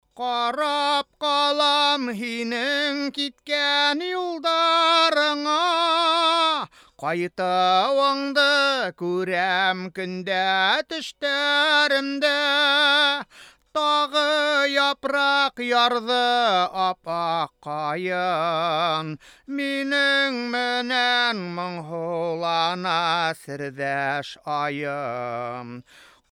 Не верю! Neumann ли это?
Хватит гнать на микрофон =) Если у меня в ушах зазвенит от пронзительного вокала, то почему этот мик должен это сгладить?